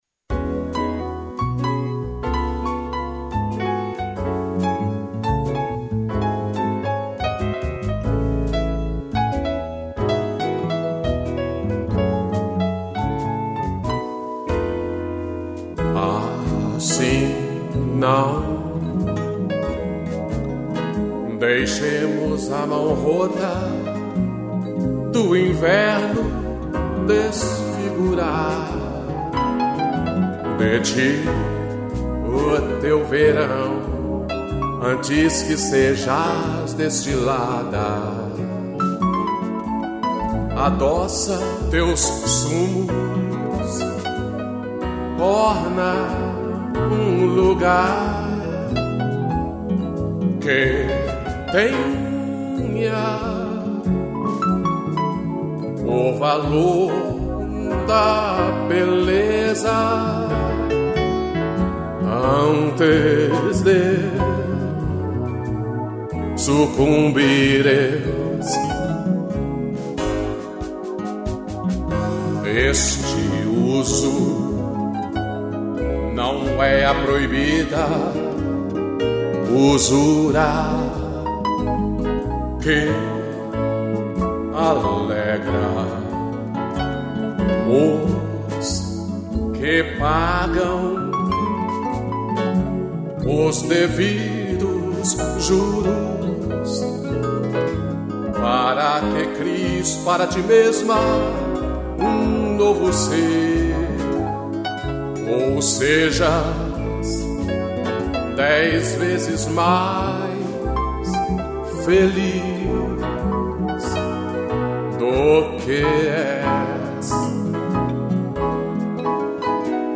interpretação e violão
piano